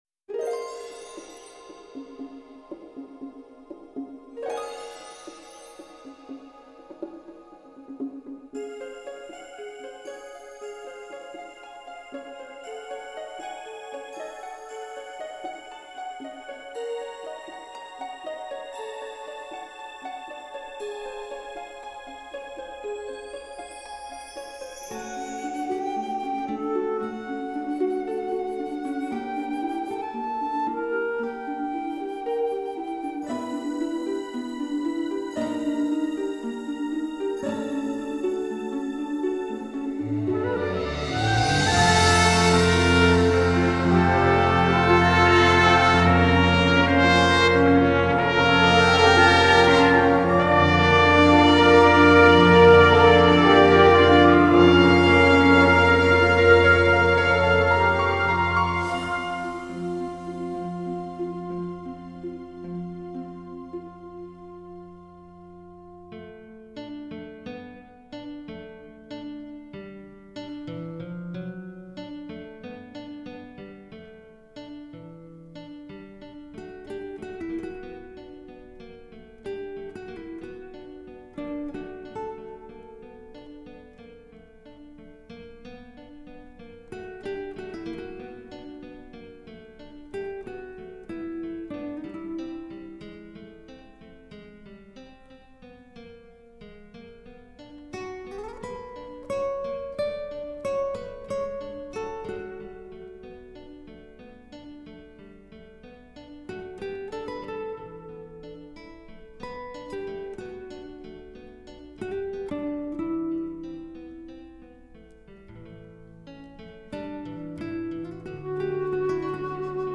I like this piece for its elegance.
Genre: Musical/Soundtrack   Composer